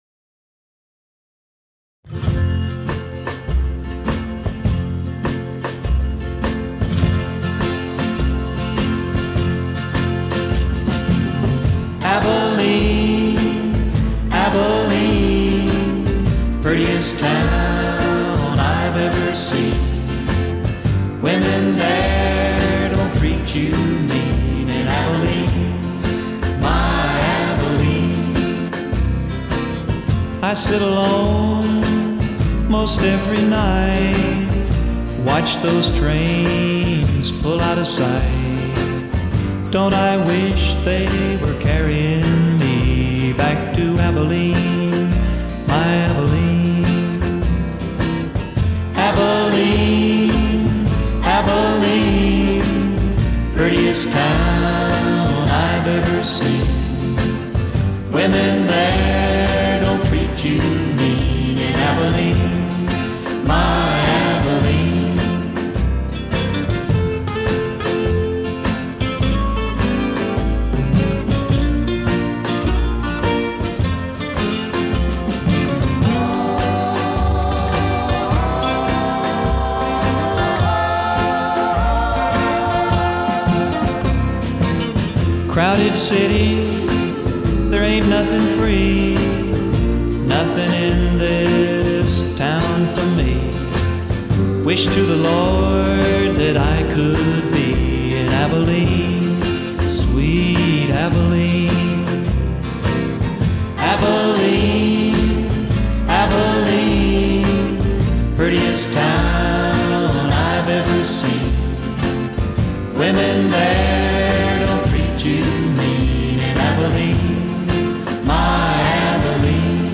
Style: Oldies 50's - 60's / Rock' n' Roll / Country